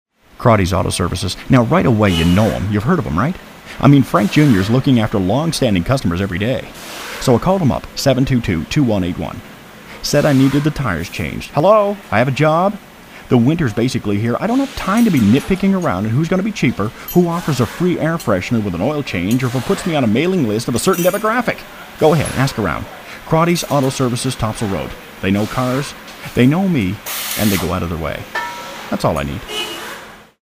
30 second conversational